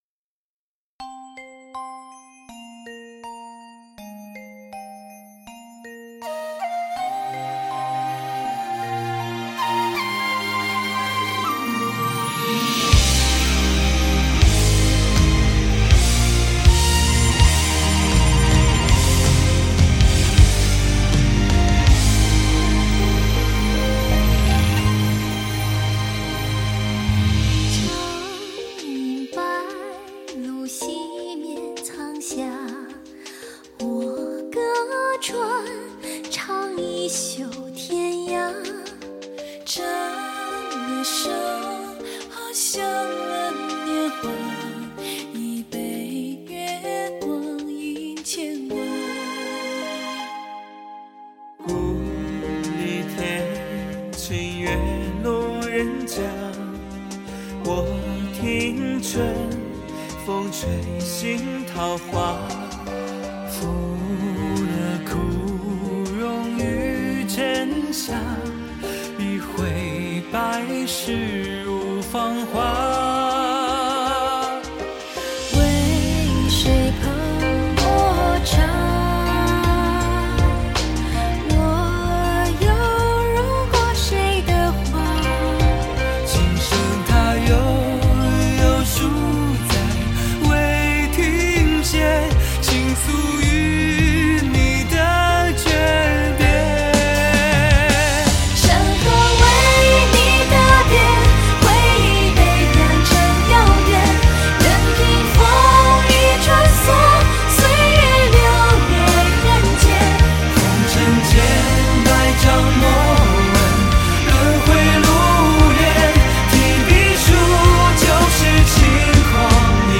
二胡
吉他
贝斯